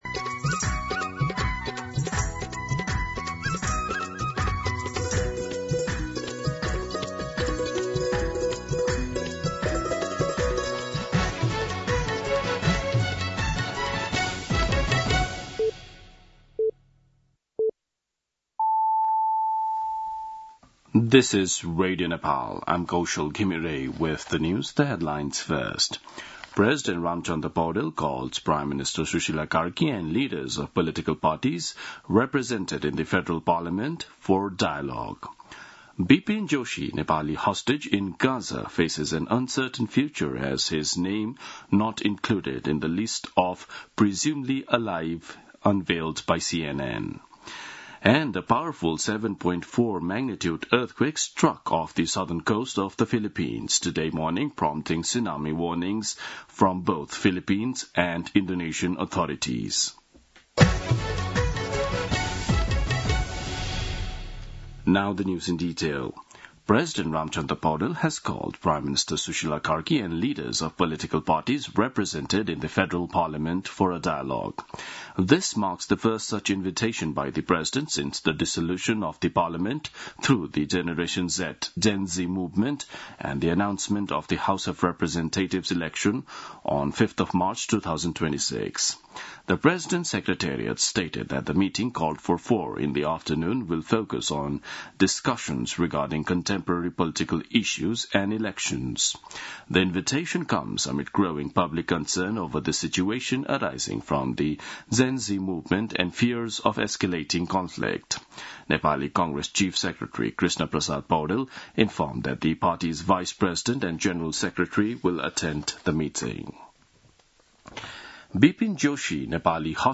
An online outlet of Nepal's national radio broadcaster
दिउँसो २ बजेको अङ्ग्रेजी समाचार : २४ असोज , २०८२